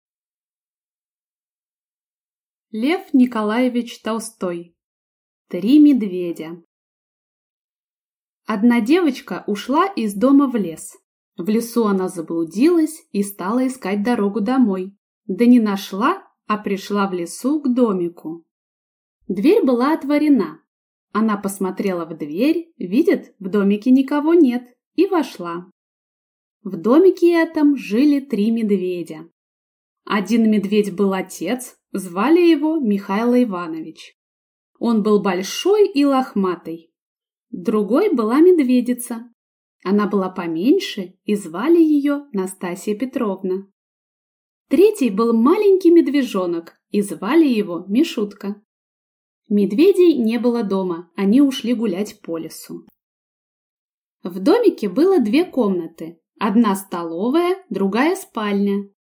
Аудиокнига Три медведя | Библиотека аудиокниг